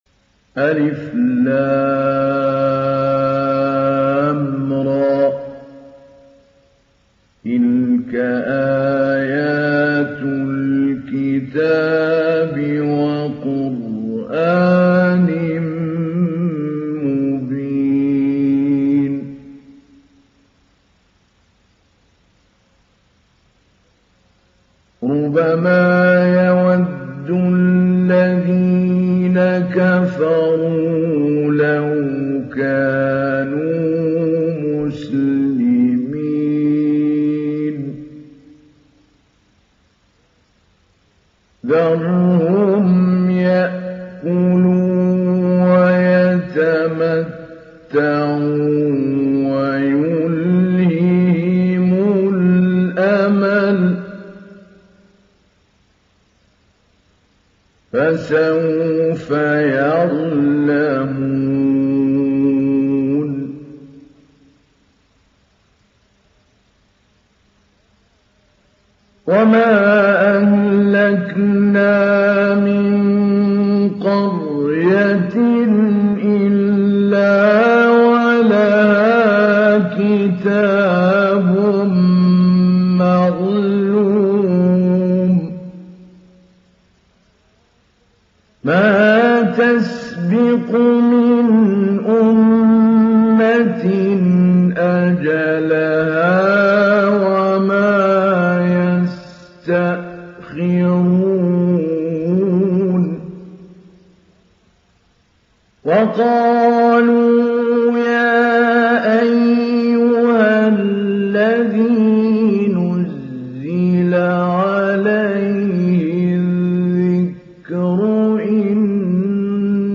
Surah Al Hijr Download mp3 Mahmoud Ali Albanna Mujawwad Riwayat Hafs from Asim, Download Quran and listen mp3 full direct links
Download Surah Al Hijr Mahmoud Ali Albanna Mujawwad